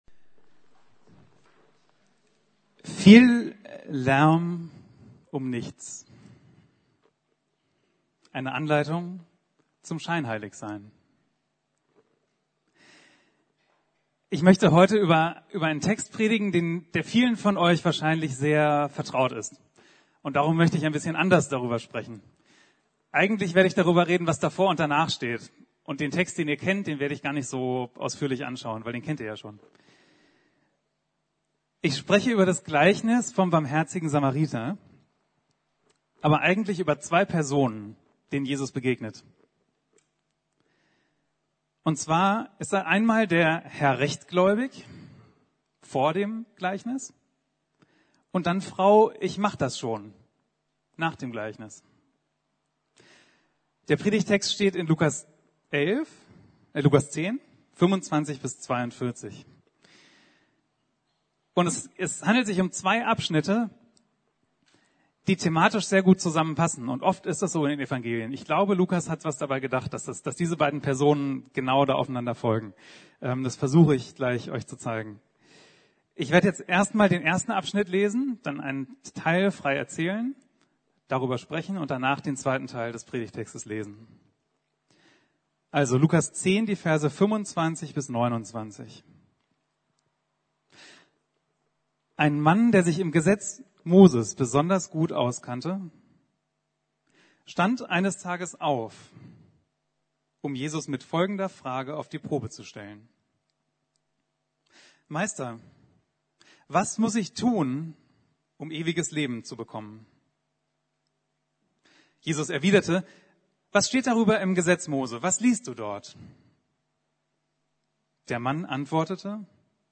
Viel Lärm um nichts - Eine Anleitung zum scheinheilig sein ~ Predigten der LUKAS GEMEINDE Podcast